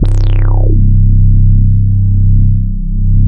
84 BASS 2 -L.wav